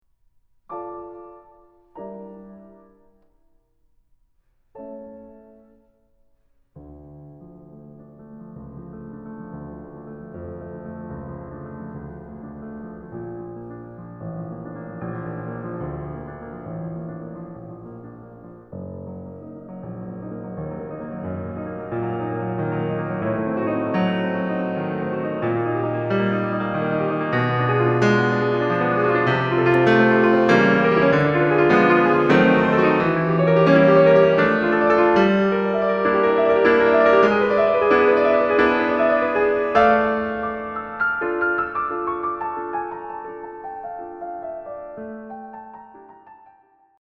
But not before one of the most incredible and extraordinary crescendos I know of in piano music. All those pent-up feelings come out.